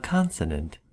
Ääntäminen
Ääntäminen : IPA : /ˈkɑn.sə.nənt/